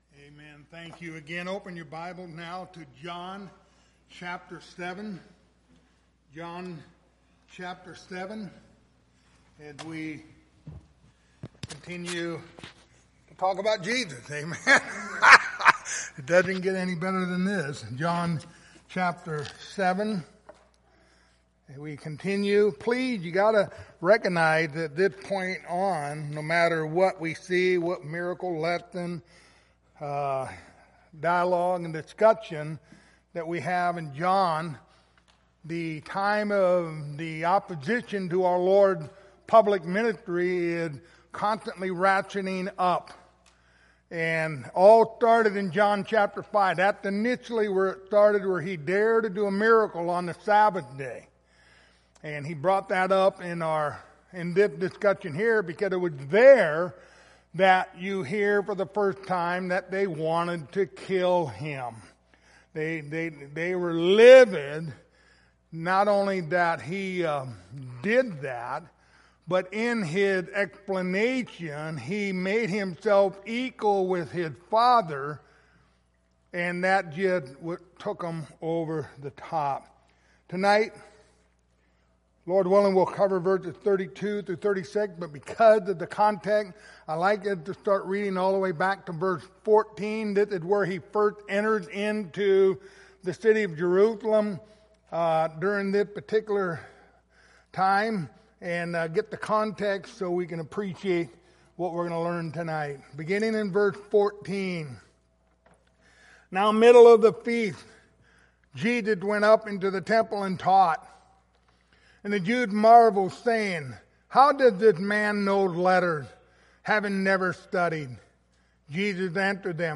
Passage: John 7:32-36 Service Type: Wednesday Evening